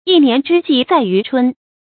一年之計在于春 注音： ㄧˋ ㄋㄧㄢˊ ㄓㄧ ㄐㄧˋ ㄗㄞˋ ㄧㄩˊ ㄔㄨㄣ 讀音讀法： 意思解釋： 要在一年（或一天）開始時多做并做好工作，為全年（或全天）的工作打好基礎。